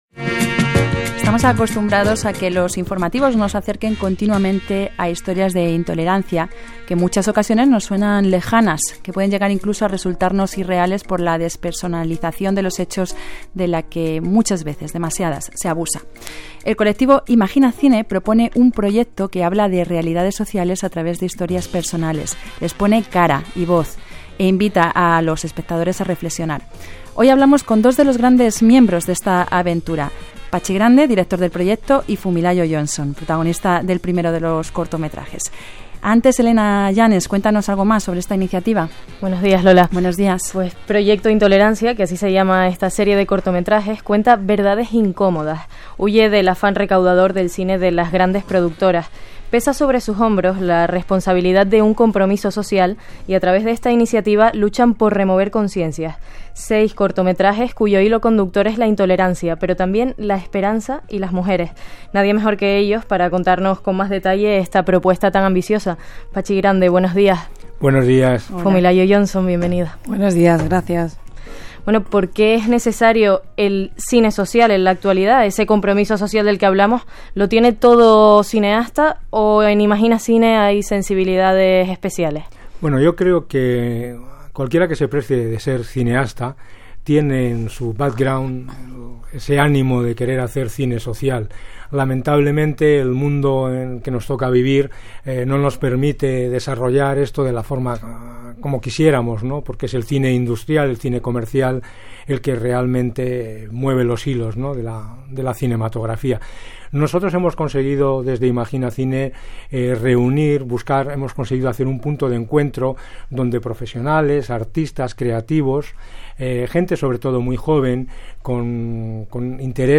Entrevista LGN Radio